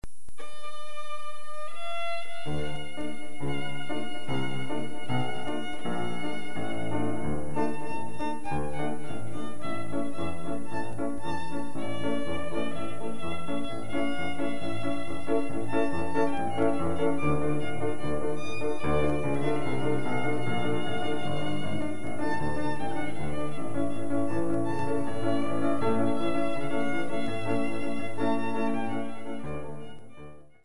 CSARDAS interprčte ŕ la façon tzigane des styles de musique divers comme:
- folklore russe: